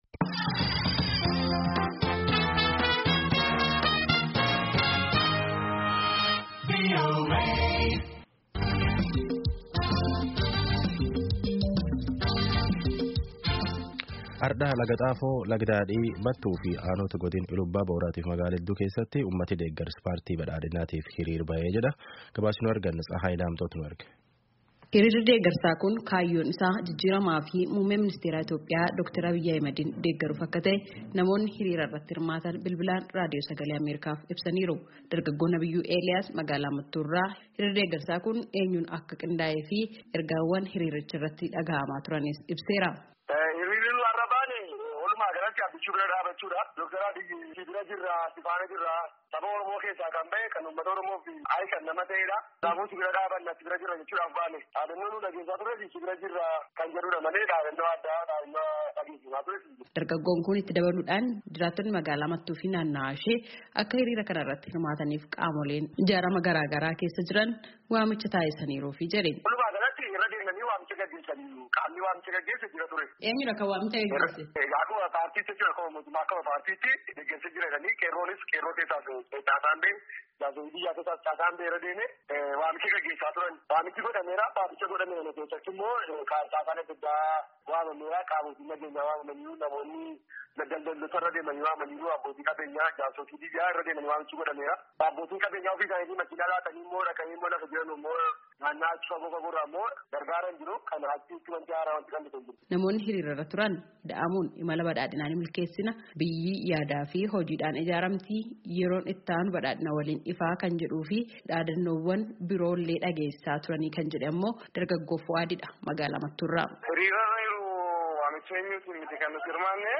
Magaalaa Lagaxaafoo lagadaadhii, magaalaa Mattuu fi aanota godinaa Illuu Abbaaboor kanneen biroo fi magaaloota biroo keessatti hiriirawwan geggeessamuu, hirimaattonnii fi aangawonni mootummaa dubbatanii jiran. Gabaasaa guutuu caqasaa